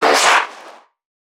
NPC_Creatures_Vocalisations_Infected [35].wav